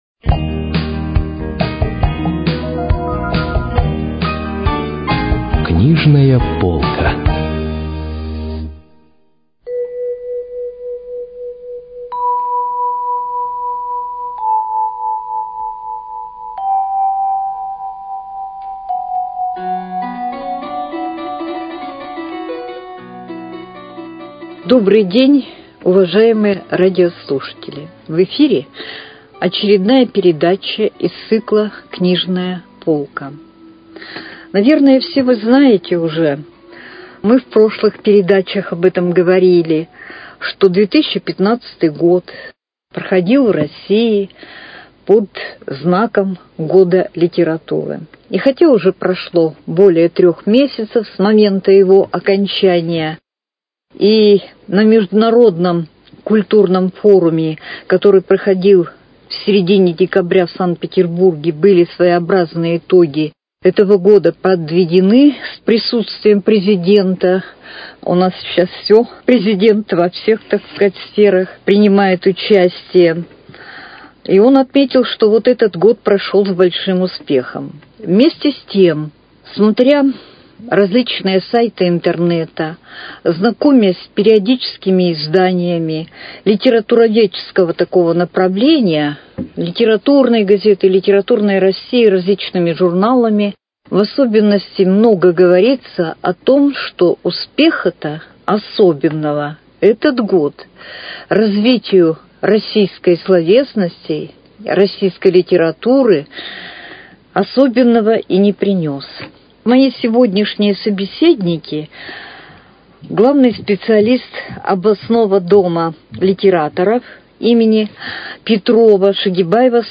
Передача из цикла «Книжная полка».